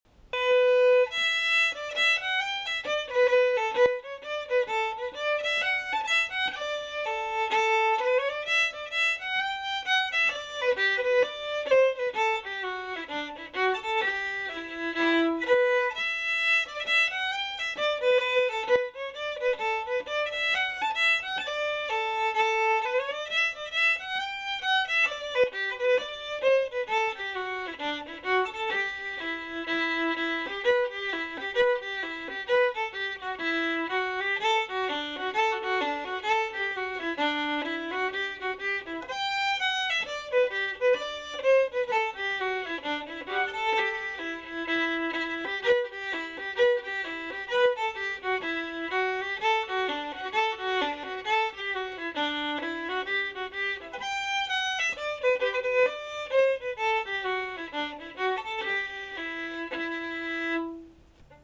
Session Tunes